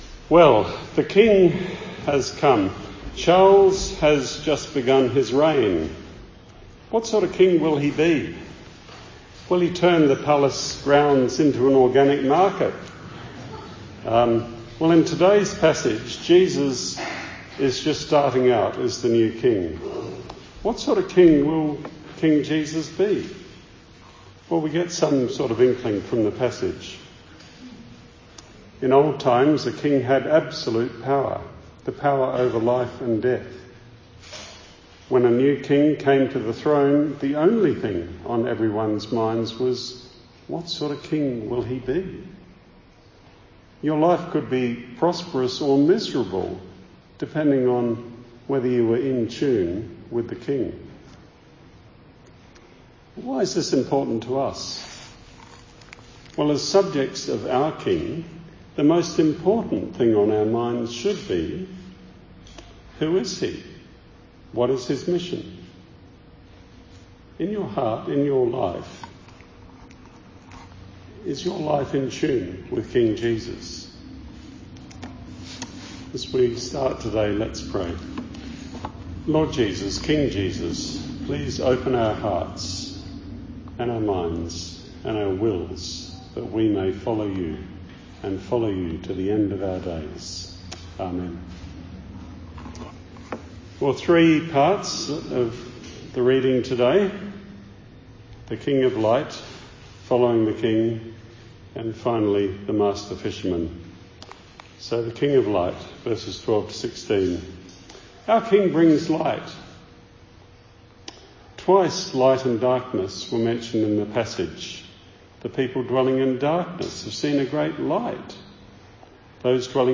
Sermon Mt 4:12-25.